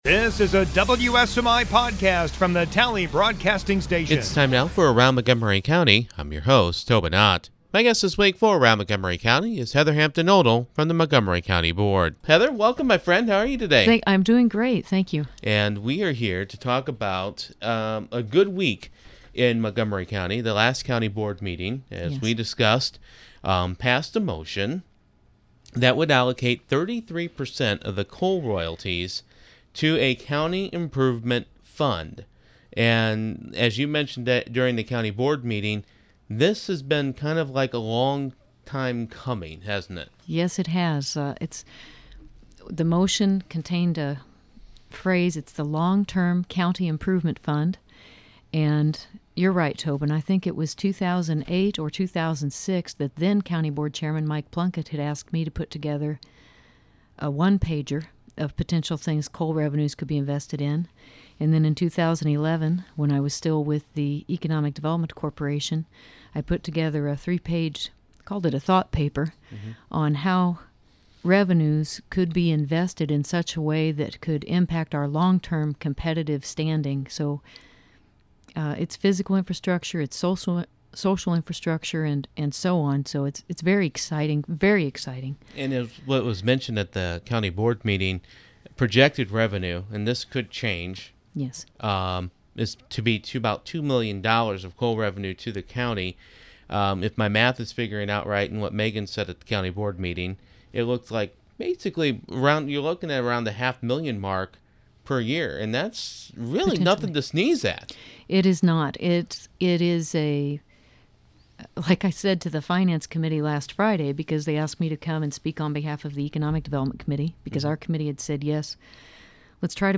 Guest: Heather Hampton-Knodle from the Montgomery County Board